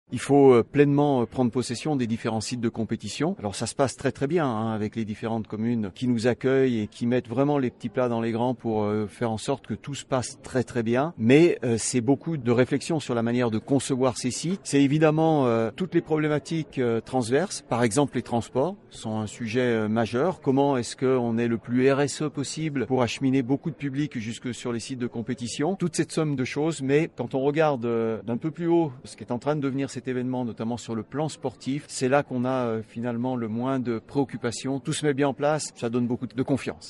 Les principaux acteurs de l’organisation, les élus ainsi que de nombreux athlètes se sont réunis, ce jeudi 23 avril 2026, au Congrès Impérial, à Annecy, pour présenter les contours de cet évènement spectaculaire. 14 sites ont été retenus et confirmés sur le département, avec le vélodrome de Saint-Quentin-en-Yvelines.